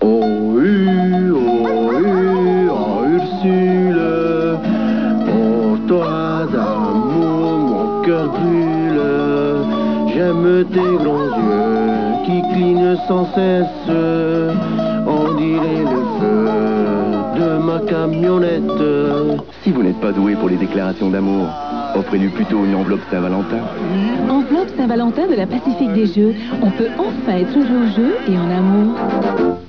Publicités radio